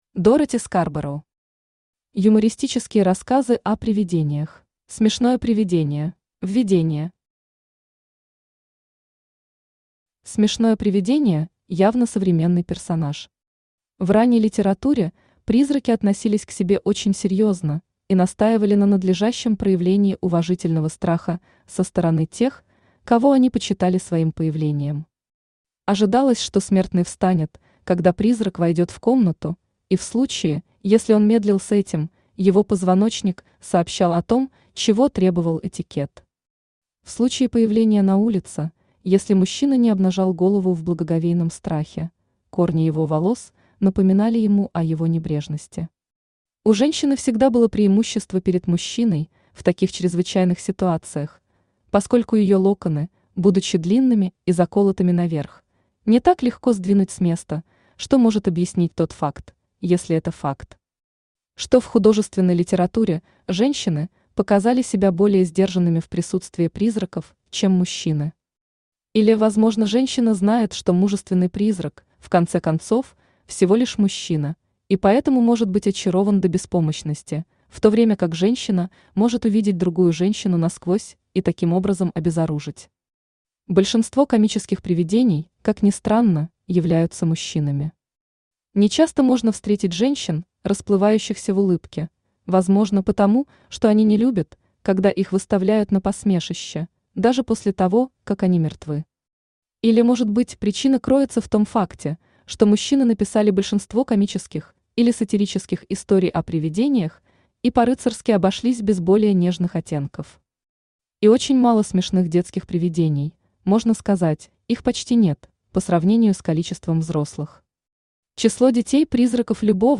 Аудиокнига Юмористические рассказы о привидениях | Библиотека аудиокниг
Aудиокнига Юмористические рассказы о привидениях Автор Дороти Скарбороу Читает аудиокнигу Авточтец ЛитРес.